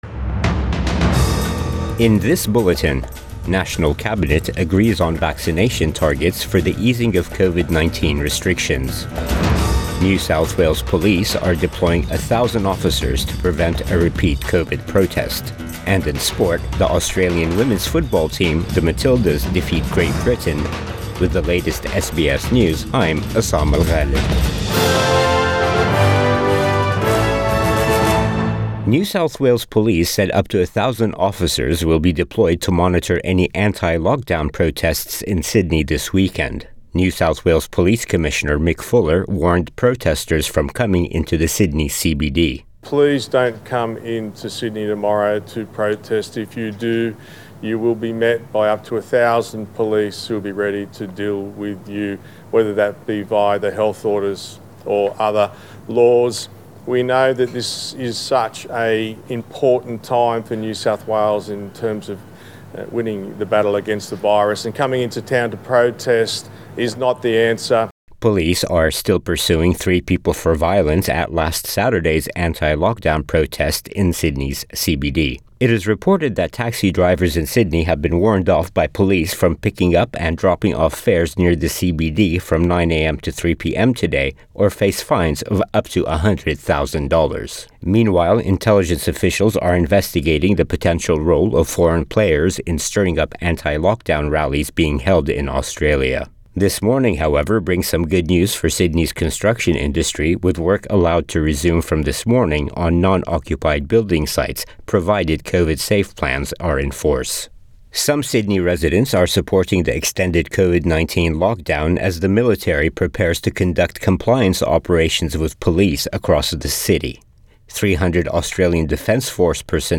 AM bulletin 31 July 2021